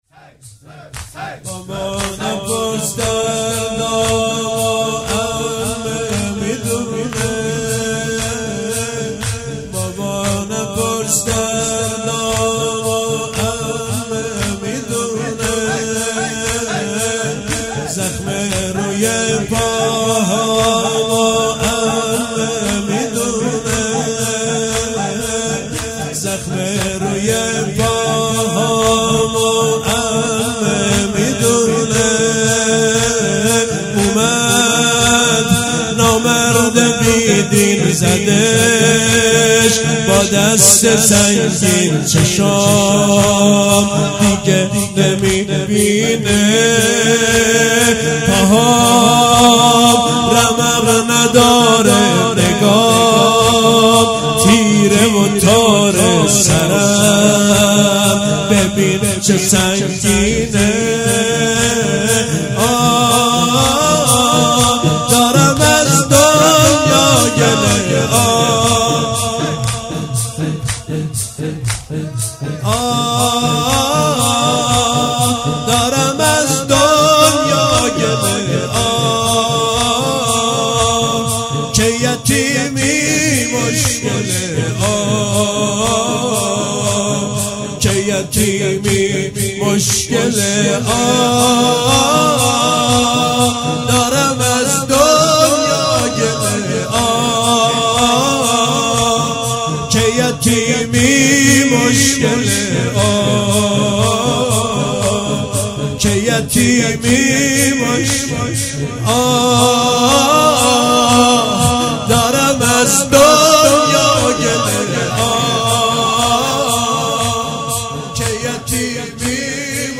شب سوم محرم - زمینه - بابا نپرس دردامو عمه میدونه